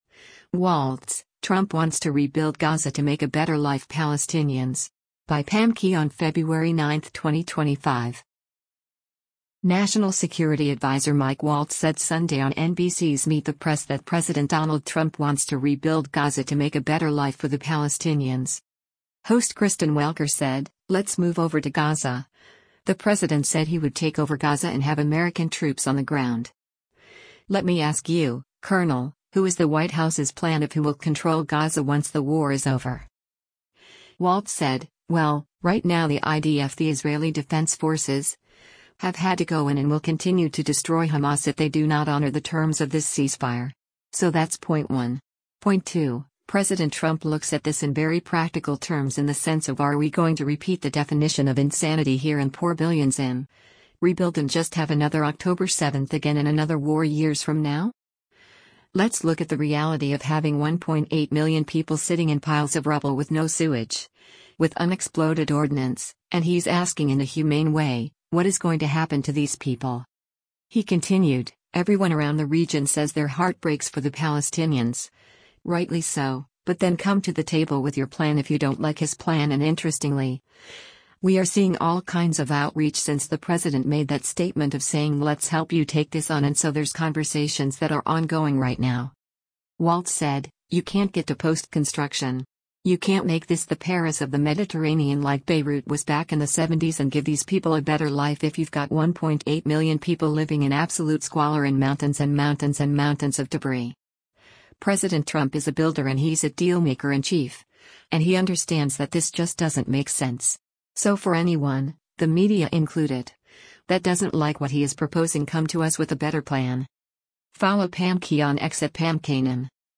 National security adviser Mike Waltz said Sunday on NBC’s “Meet the Press” that President Donald Trump wants to rebuild Gaza to make a “better life” for the Palestinians.